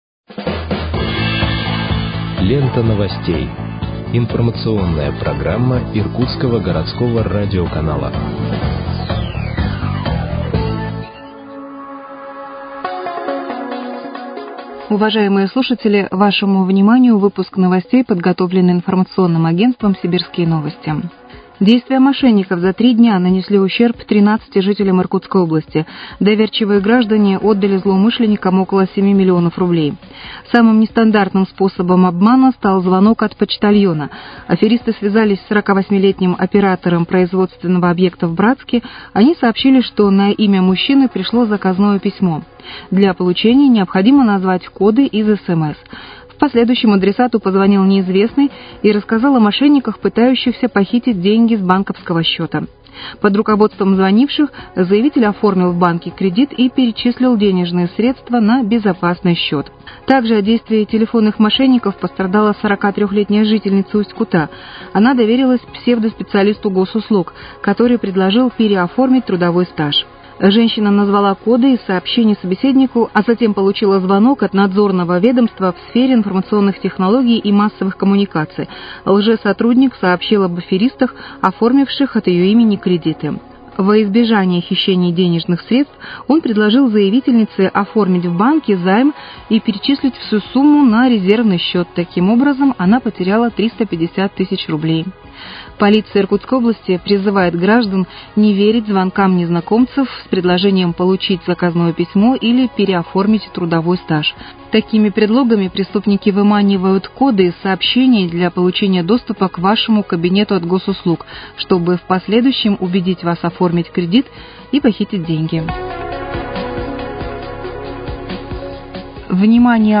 Выпуск новостей в подкастах газеты «Иркутск» от 16.10.2024 № 2